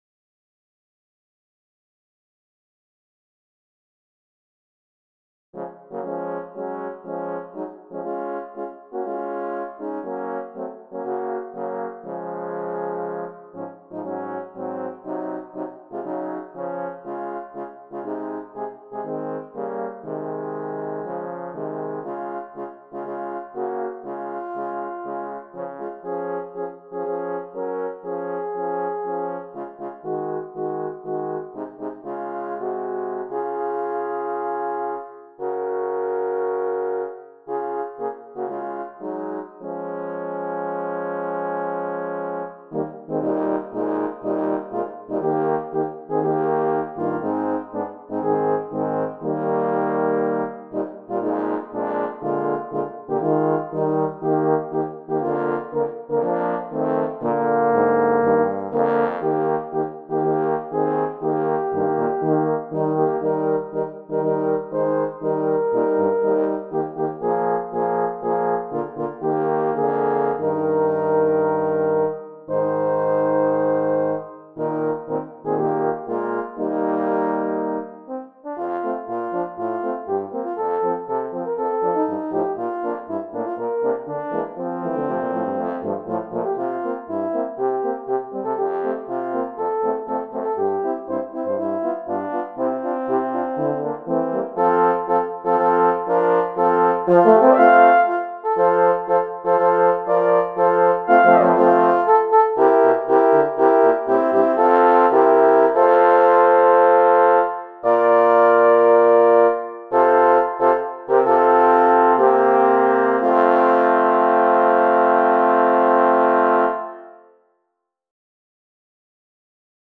Here is THE WAY OF THE CROSS LEADS HOME arranged for horn quartet.